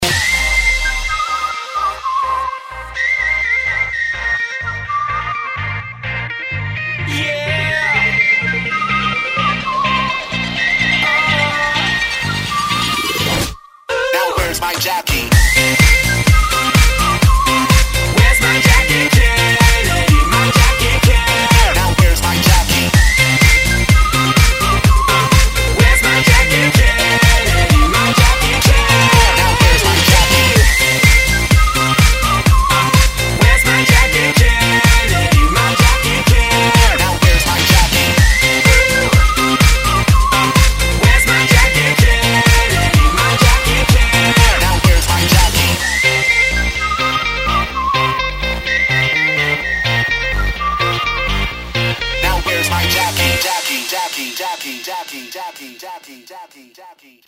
• Качество: 128, Stereo
позитивные
свист
электронная музыка
бодрые
Whistling